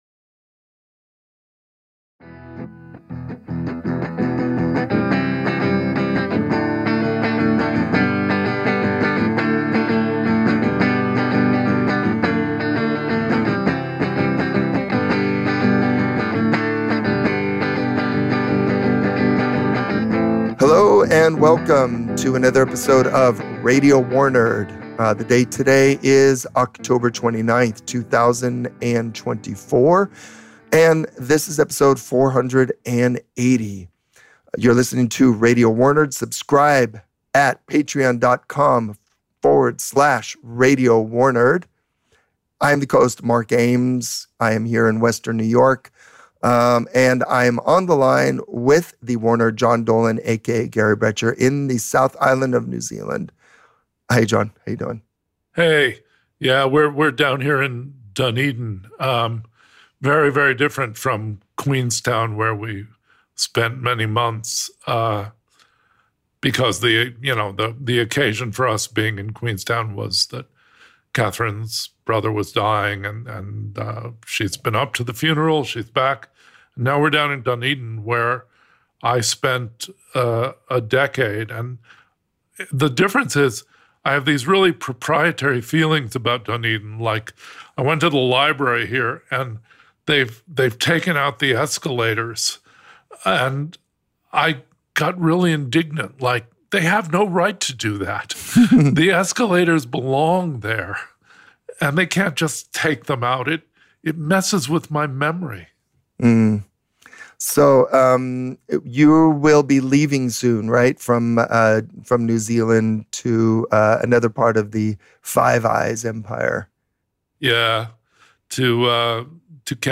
Music interlude